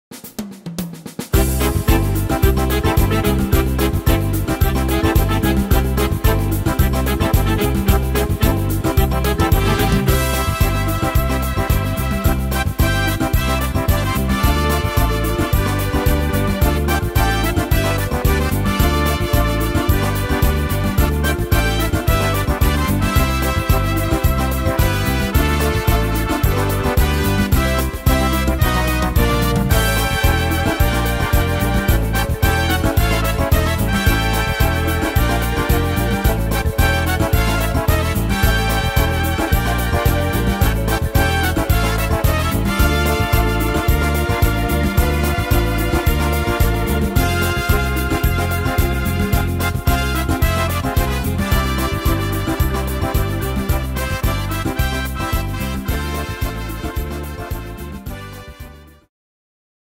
Tempo: 110 / Tonart: C-Dur